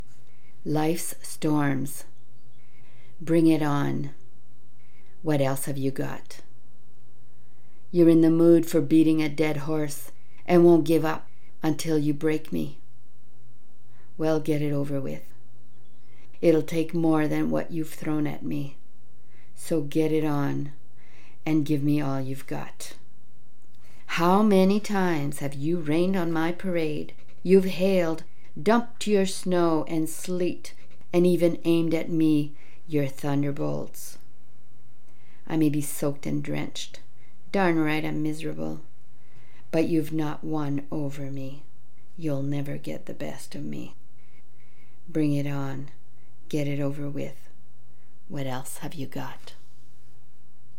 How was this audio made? Read on air by invitation ~ March 7, 2021 'LATE NIGHT POETS'